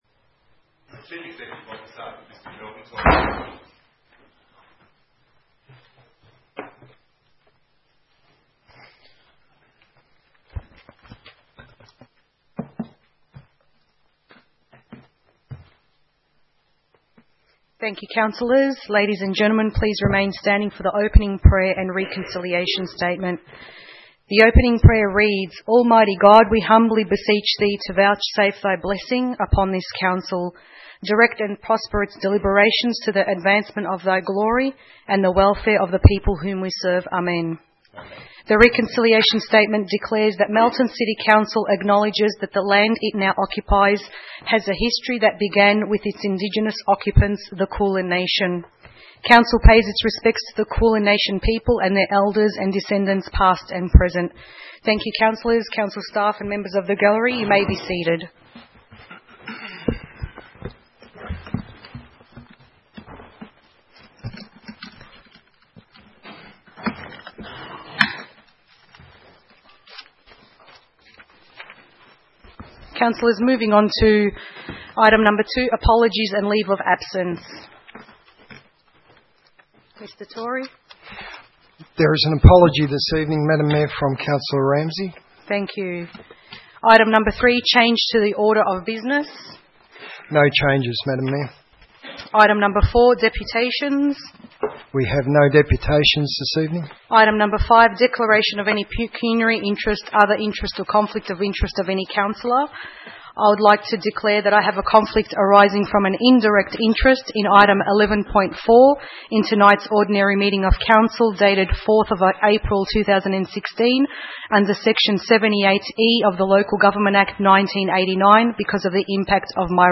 4 April 2016 - Ordinary Council Meeting